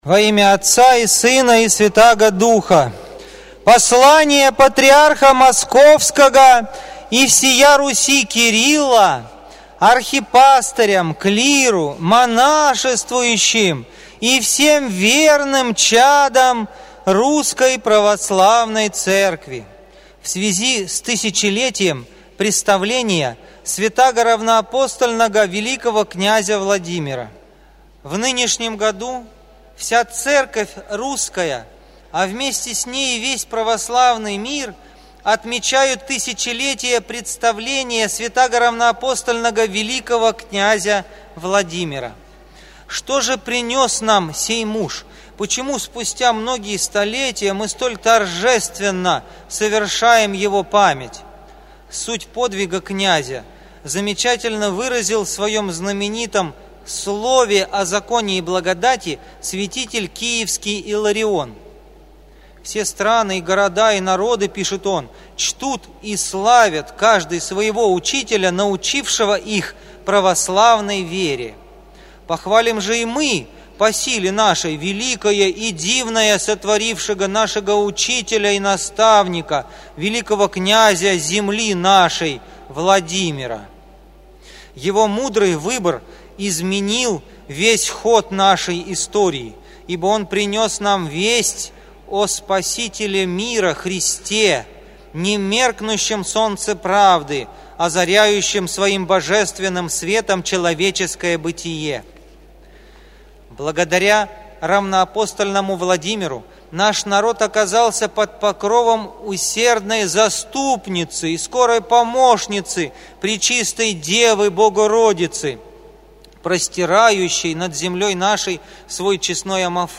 Послание Патриарха читает иерей